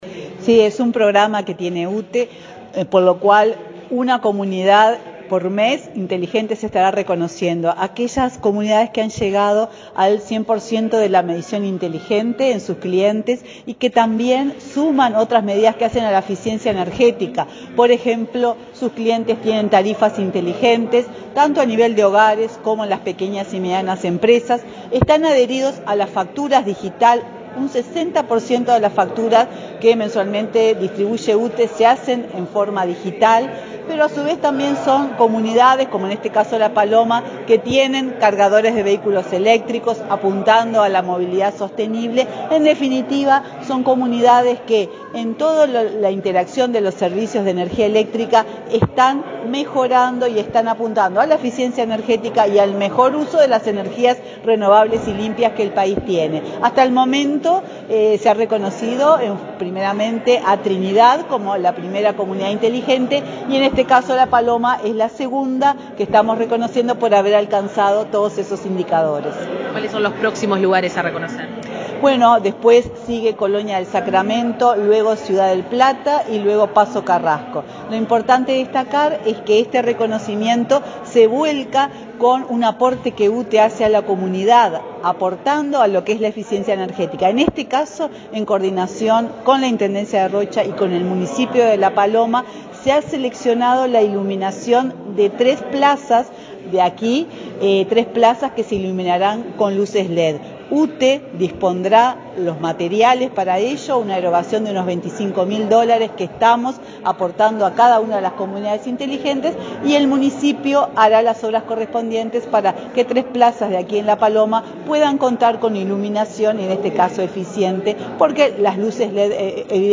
Entrevista a la presidenta de UTE, Silvia Emaldi
La presidenta de la UTE, Silvia Emaldi, dialogó con Comunicación Presidencial en Rocha, donde las autoridades del ente reconocieron como Comunidad